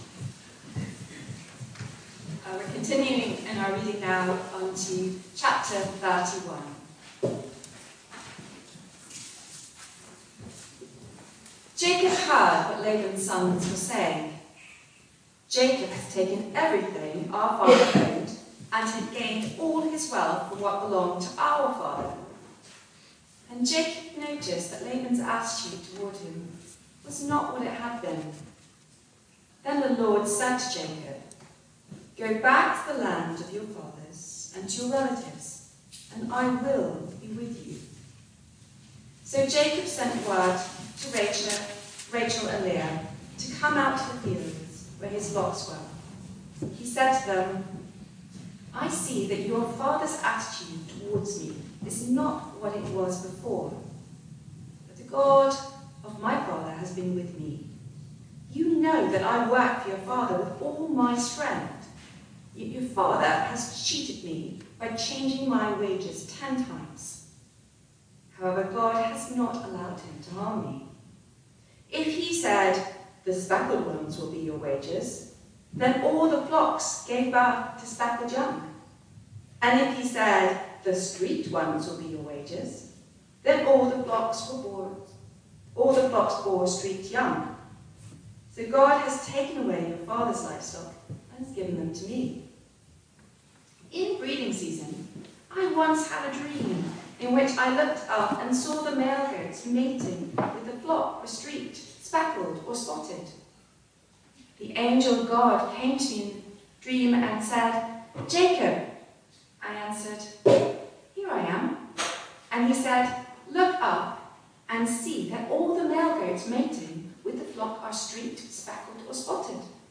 Barkham Morning Service
Reading & Sermon